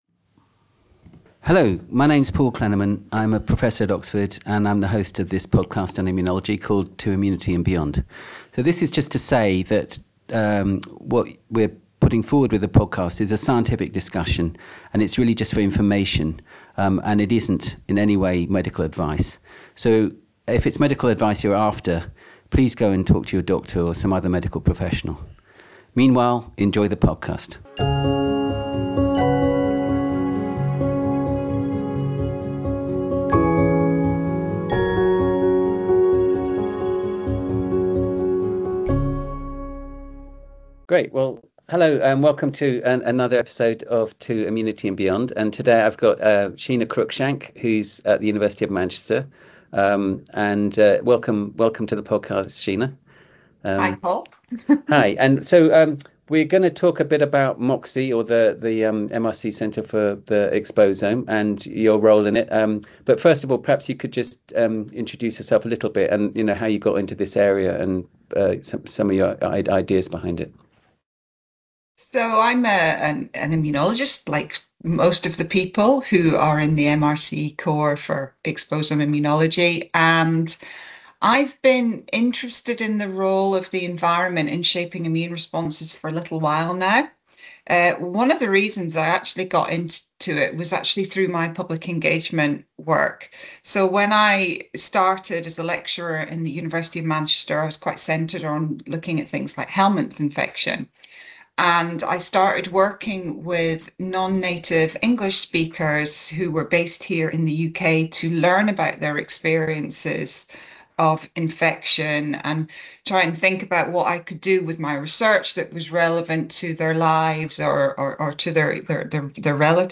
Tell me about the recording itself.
The conversation also covers interdisciplinary team science, patient and community involvement, and how the Centre is building datasets and infrastructure that can support future discovery.